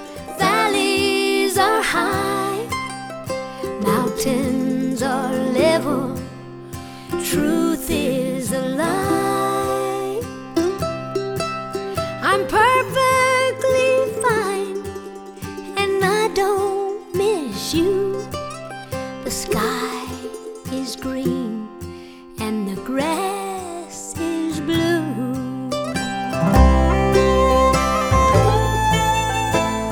• Honky Tonk